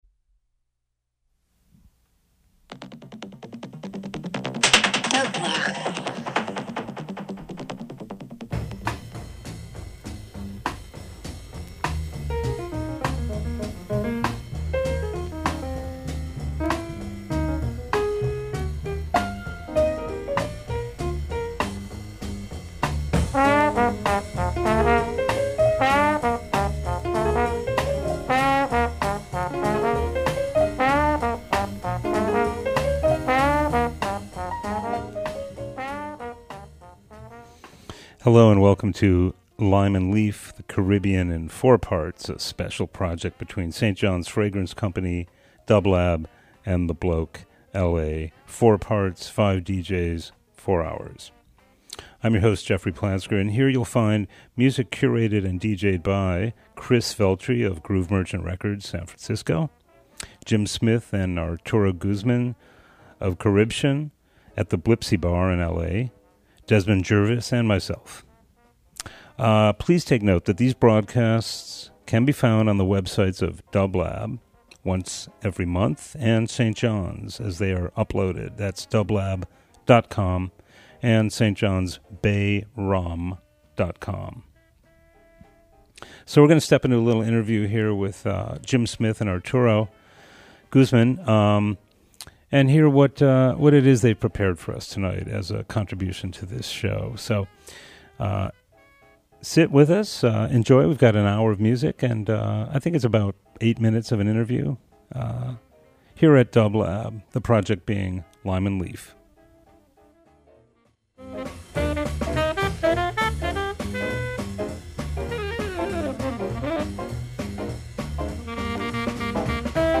Music with a strong dance tradition and afro-latin twist.